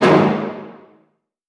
Scare_v4_wav.wav